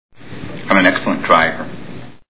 Rain Man Movie Sound Bites